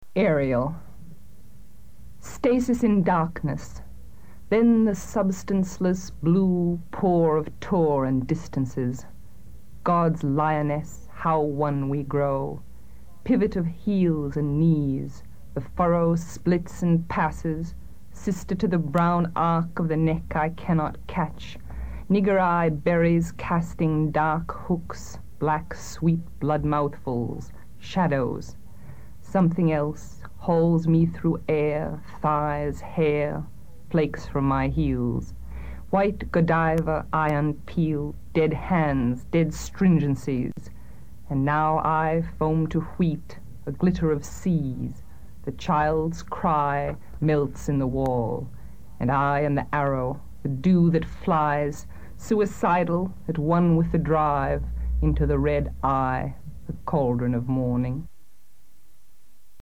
J’ai lu � voix haute pour t�cher de m’impr�gner ou, au contraire, de m’y fondre.